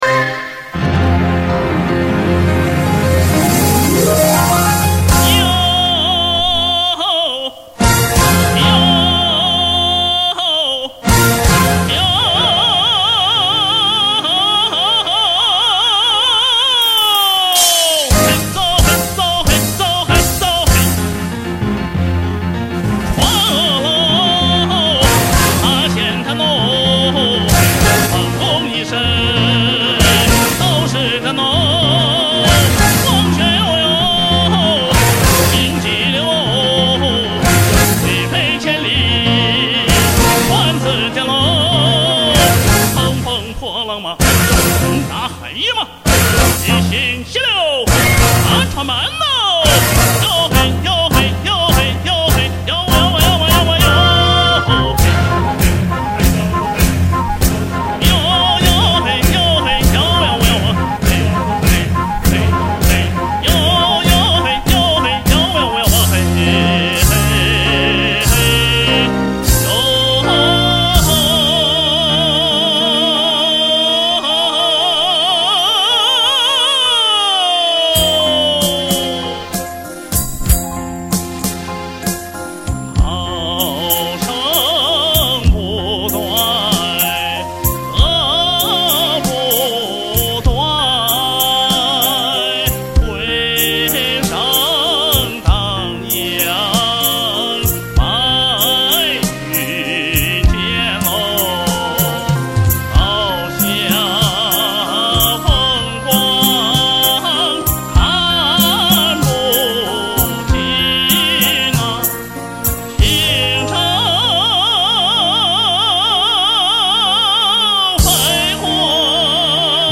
[26/4/2009]船工号子